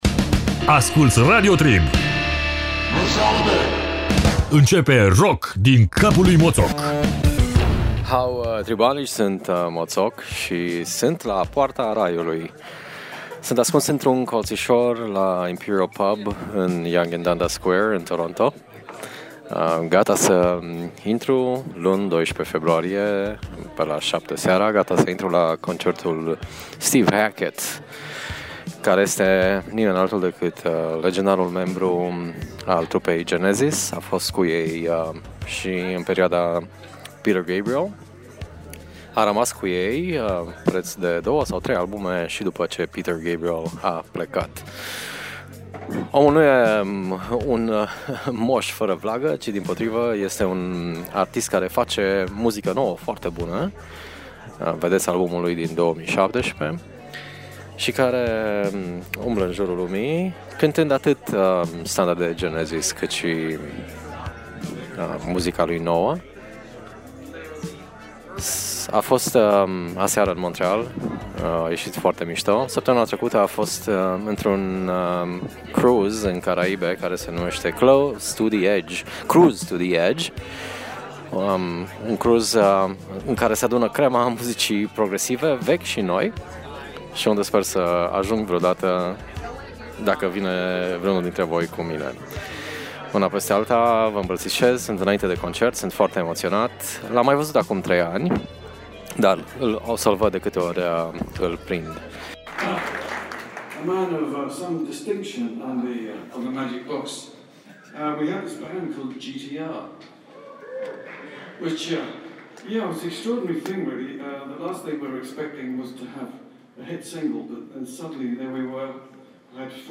In sala de concert atmosfera a fost ca un dans pe vârful unui vulcan.
In episodul de azi il auziti pe Steve Hackett povestind, in concertul de la Toronto, despre grupul GTR si colegul lui Steve Howe.